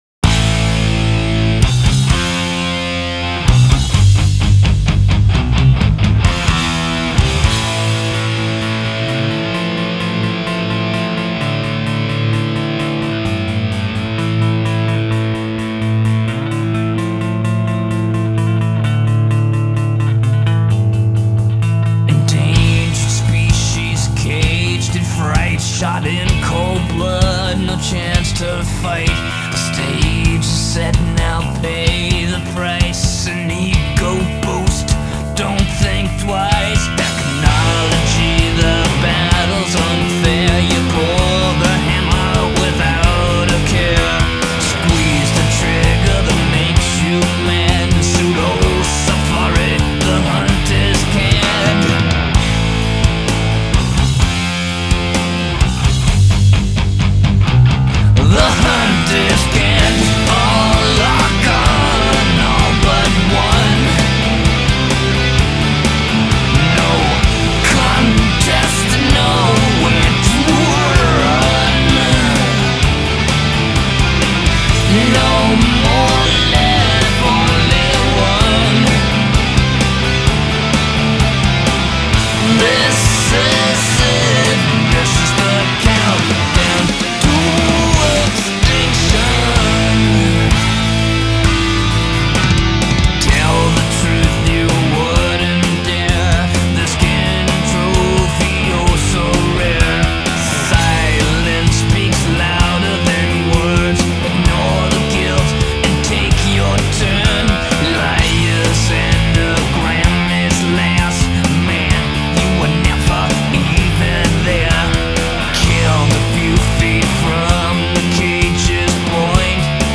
ترش متال
Thrash Metal